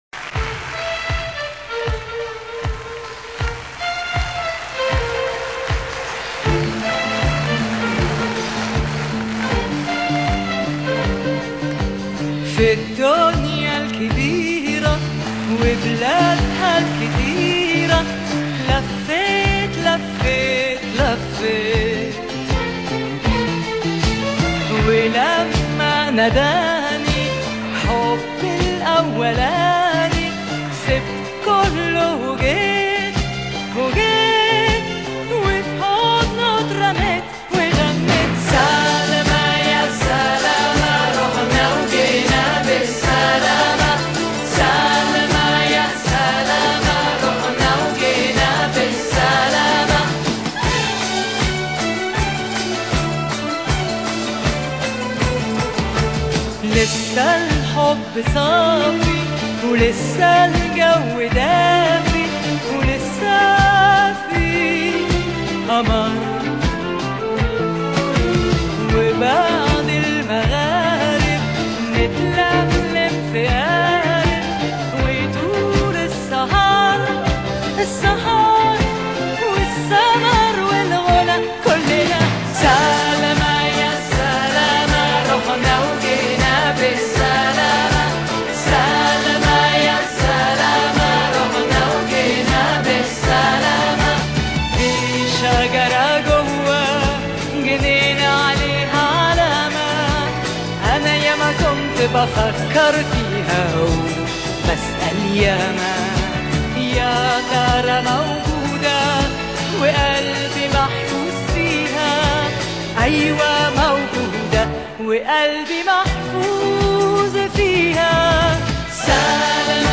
Genre: Pop, Disco, Chanson